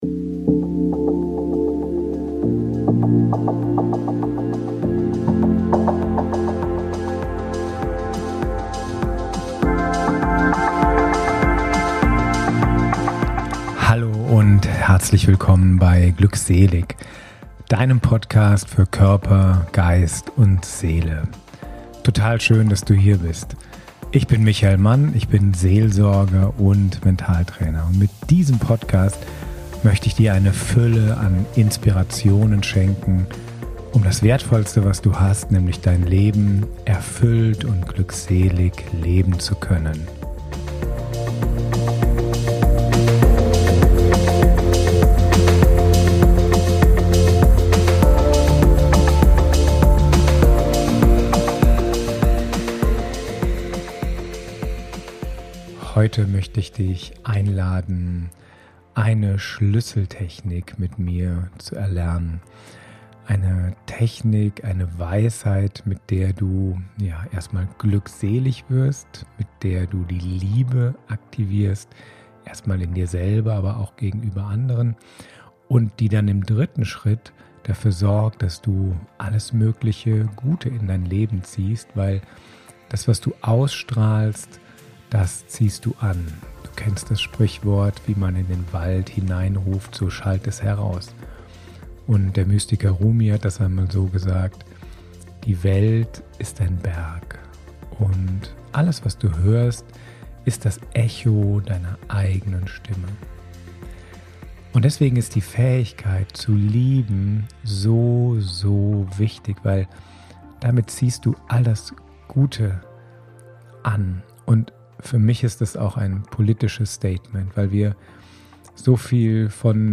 Einfach mehr Liebe in Deinem Leben - in diesem Podcast führe ich Dich durch eine Meditation, die sowohl die Selbst-, die Nächsten- wie auch die Feindesliebe aktiviert. So dass sich einfach mehr Liebe in deinem Leben in allen Bereich breit machen kann.